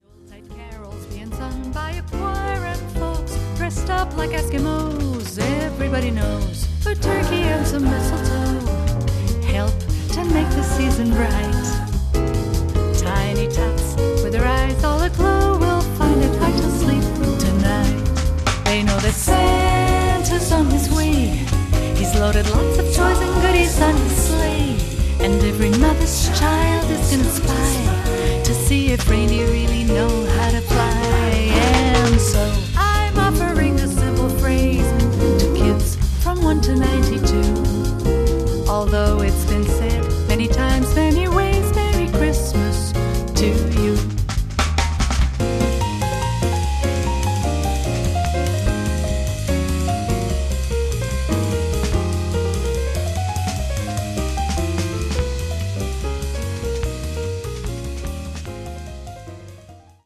tenore
contralto
soprano
pianista arrangiatore
Drums